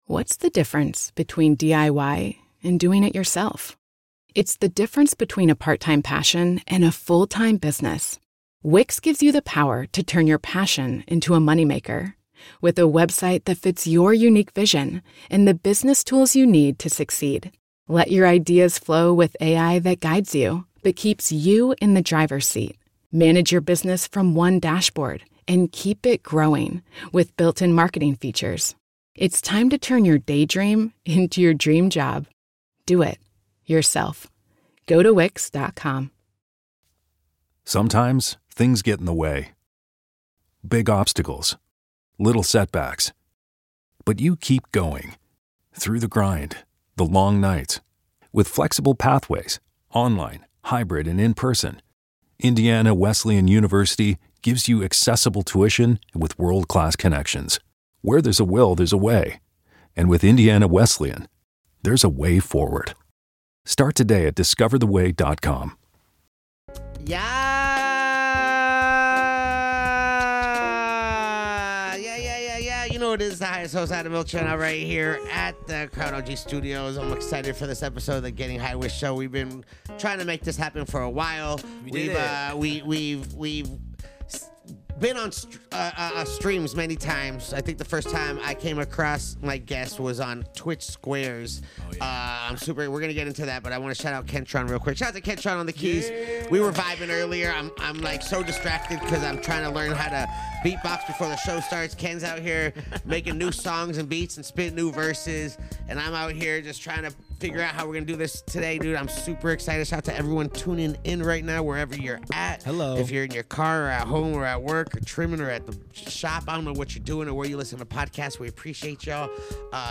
Filled with laughter and unique sounds, this episode is sure to be unforgettable.